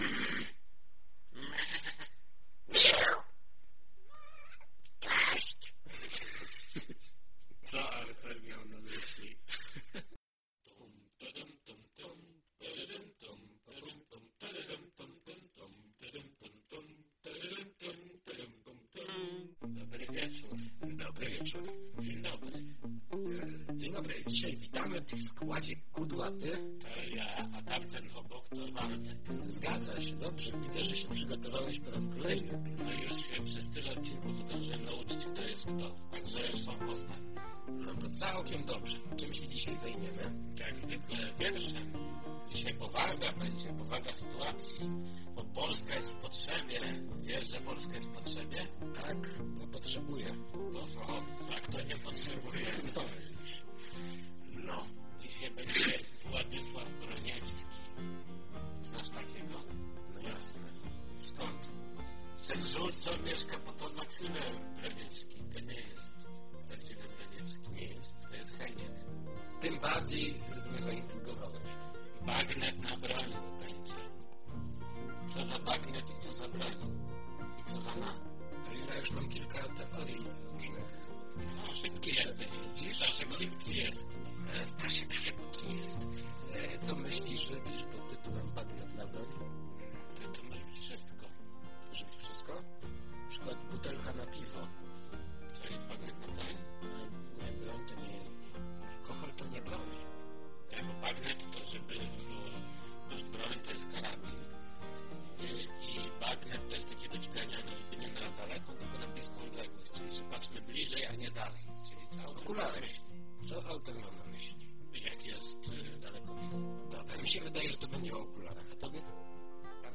"Co Ałtor Miał na Myśli" to audycja rozrywkowa, nagrywana co tydzień lub dwa.